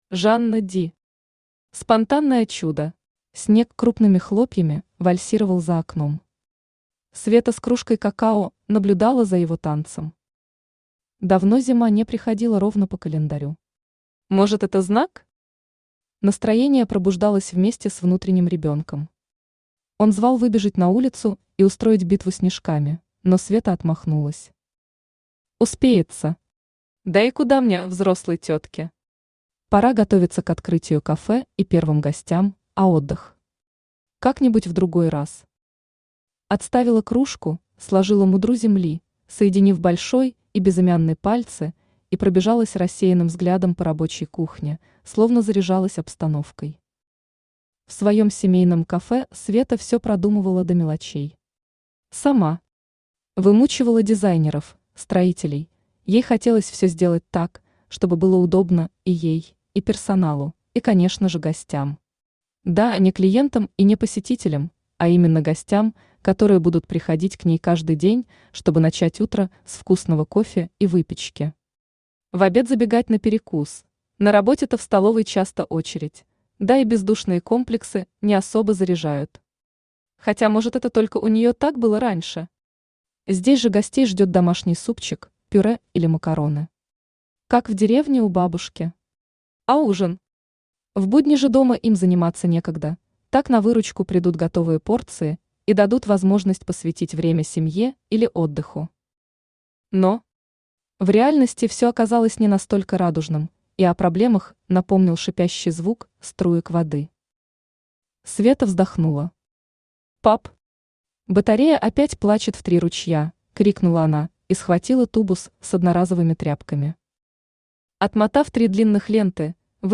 Аудиокнига Спонтанное чудо | Библиотека аудиокниг
Aудиокнига Спонтанное чудо Автор Жанна Ди Читает аудиокнигу Авточтец ЛитРес.